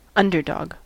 Ääntäminen
Ääntäminen US : IPA : [ˈʌn.dɚ.dɑɡ] Tuntematon aksentti: IPA : /ˈʌn.də(ɹ).dɒɡ/ IPA : /ˈʌn.də(ɹ).dɔɡ/ Haettu sana löytyi näillä lähdekielillä: englanti Käännöksiä ei löytynyt valitulle kohdekielelle.